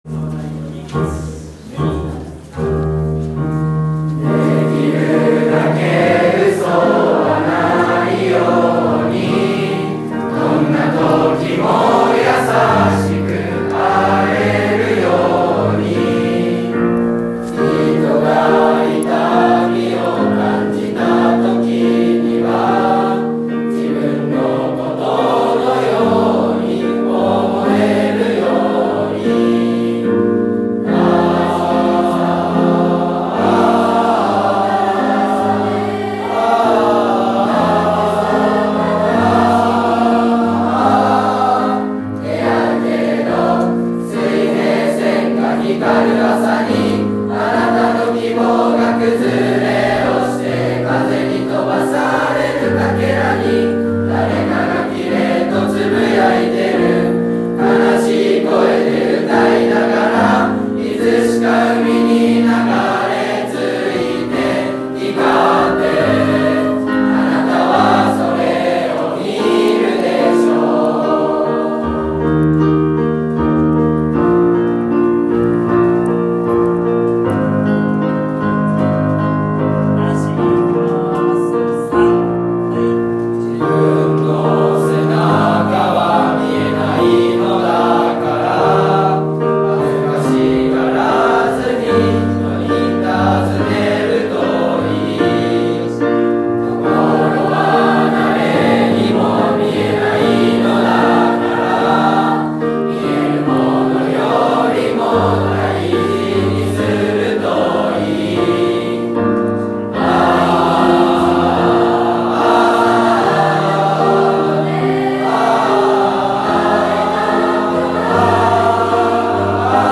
【学校生活】JHS☆We are practicing singing ‘Suiheisen by Back number’ ♪
中学校では桐華祭での全校合唱に向け、絶賛、合唱の練習中です。 9月13日14日の完成に向け、全校の生徒が心を一つに頑張ります！